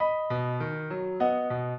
piano
minuet8-2.wav